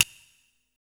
1TI98SNAP -L.wav